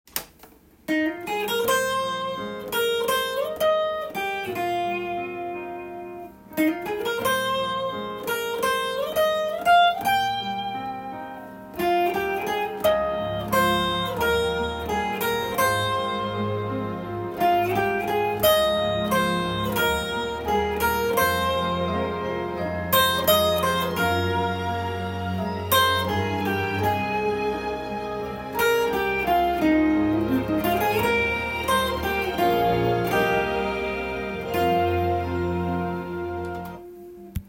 カンタン　メロディーtab譜
音源にあわせて譜面通り弾いてみました
メロディーラインがお茶を感じさせる日本らしい雰囲気になっています。
keyがD♭というギターでは弾きにくい音域になっているので
１６分音符で綺麗なリズムをキープしていきたいところです。